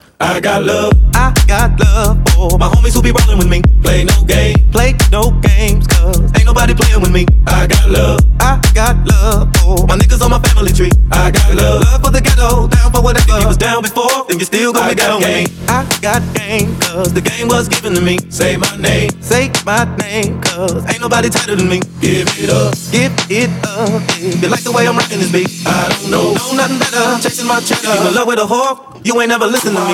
Жанр: Танцевальные
Dance